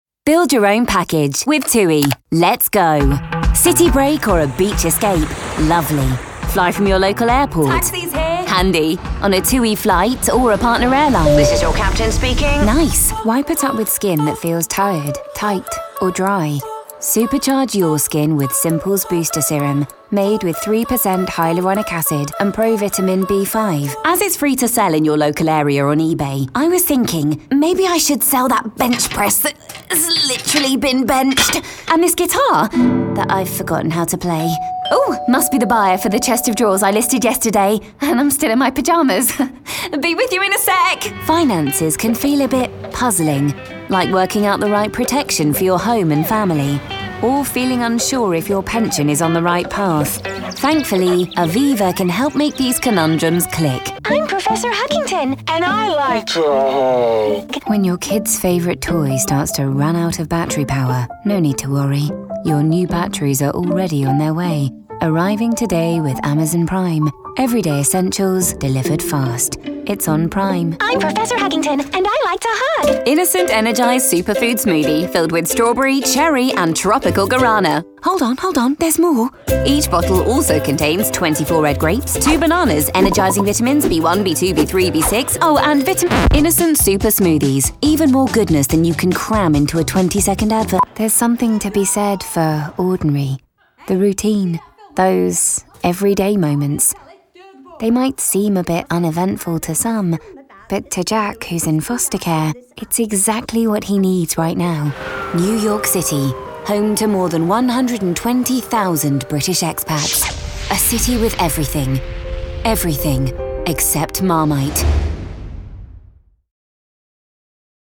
Natural, Playful, Versatile, Friendly, Warm
Commercial